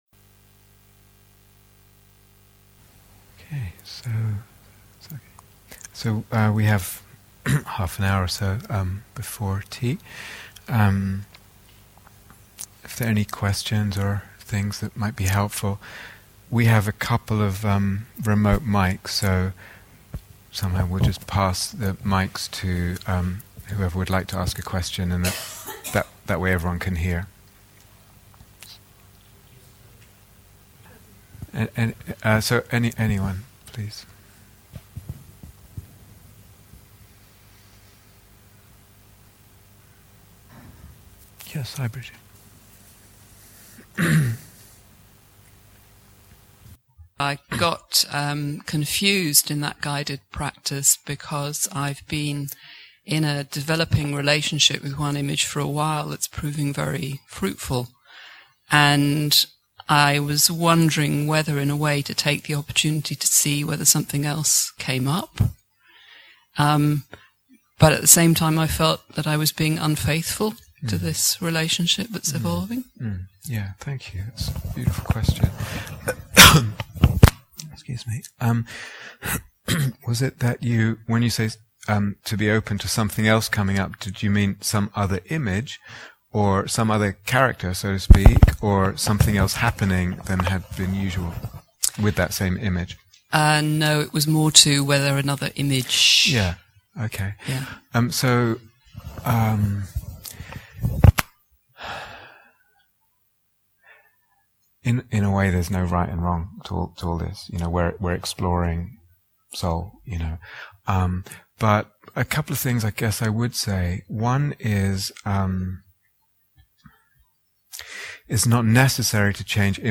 Faith in Soulmaking (Q & A)